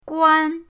guan1.mp3